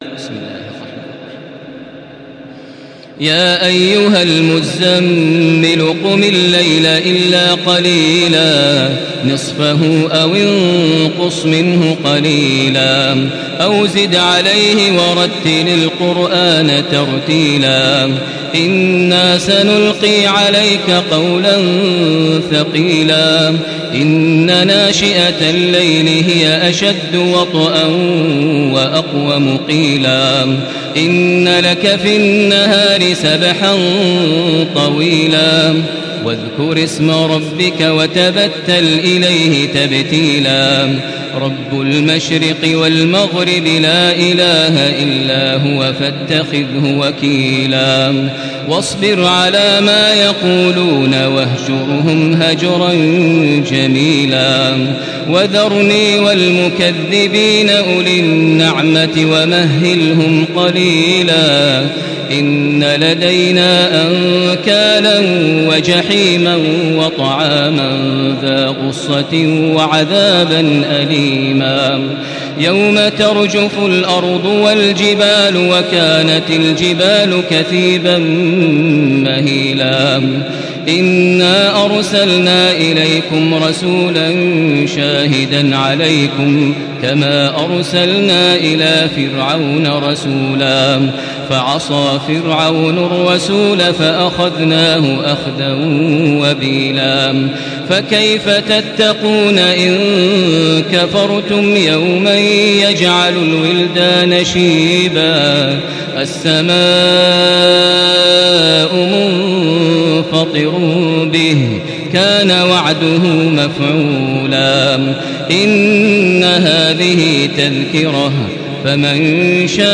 Makkah Taraweeh 1435
Murattal